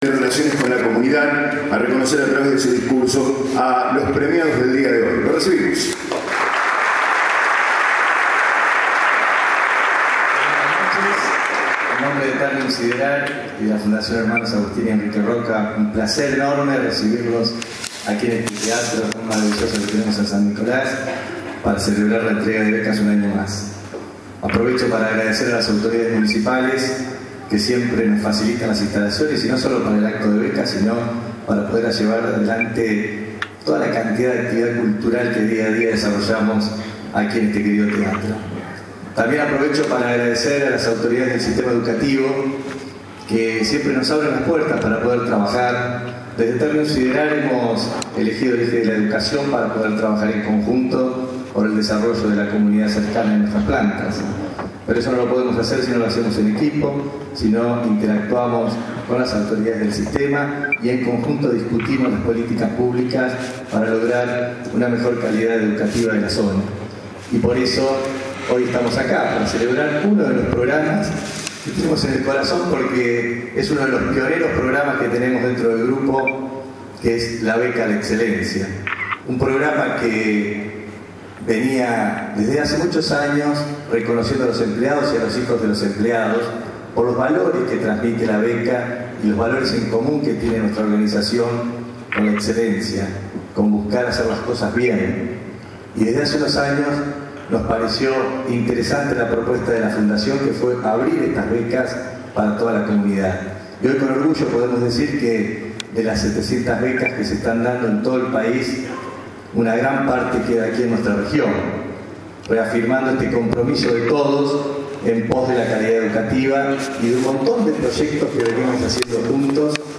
Los días miércoles 5 de julio y jueves 6 de julio se realizó en el Teatro Municipal de San Nicolás los actos donde se entregaron las Becas al Mérito que otorga la Fundación Hermanos Agustín y Enrique Rocca.